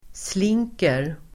Uttal: [sl'ing:ker]